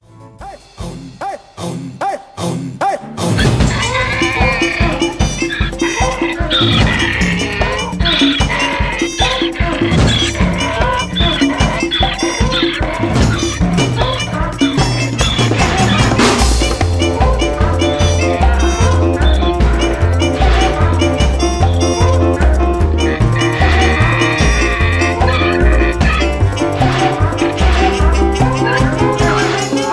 Lustiger Eseltanz.